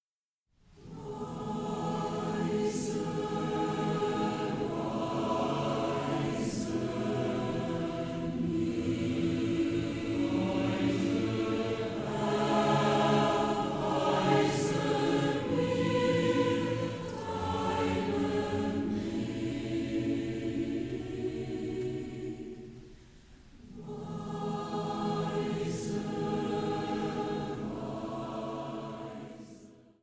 • kurzweilige Zusammenstellung verschiedener Live-Aufnahmen
Chor